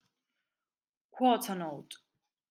NEGRA QUARTER NOTE /ˈkwɔːtə nəʊt/